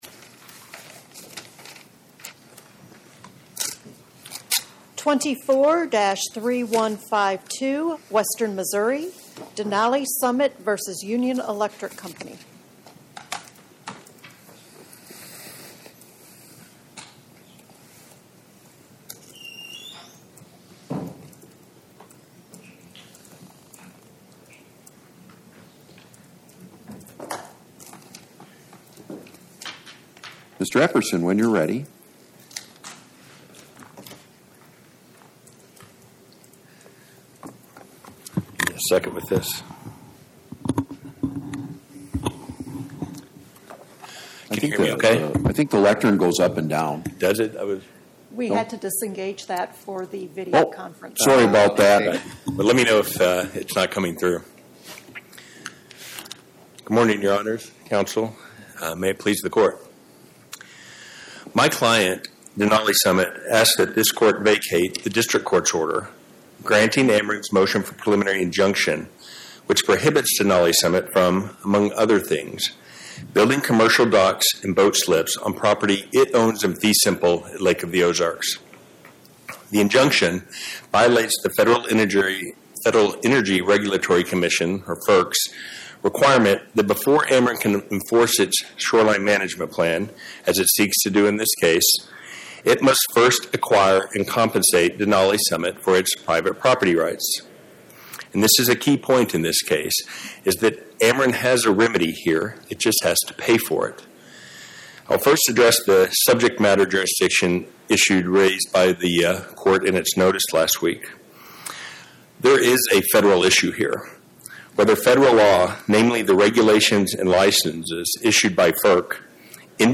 My Sentiment & Notes 24-3152: Denali Summit, LLC vs Union Electric Company Podcast: Oral Arguments from the Eighth Circuit U.S. Court of Appeals Published On: Thu Sep 18 2025 Description: Oral argument argued before the Eighth Circuit U.S. Court of Appeals on or about 09/18/2025